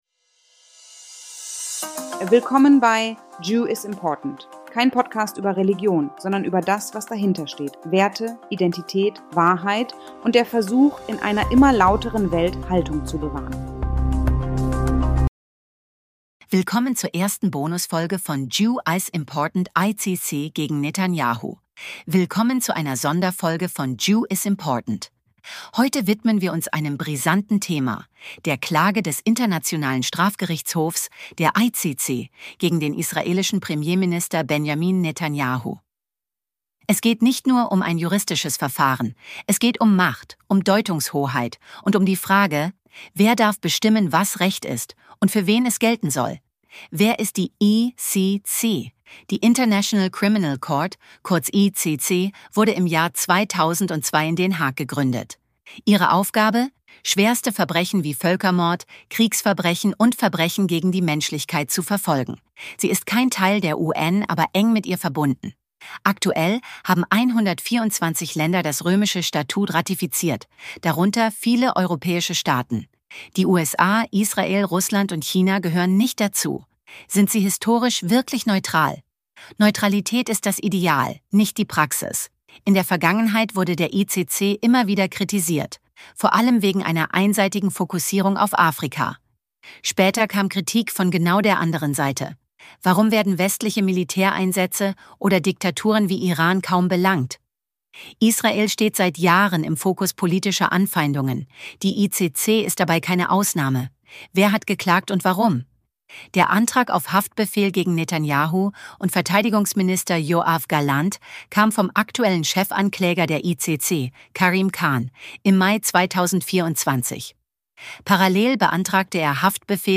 © 2025 AI-generated content.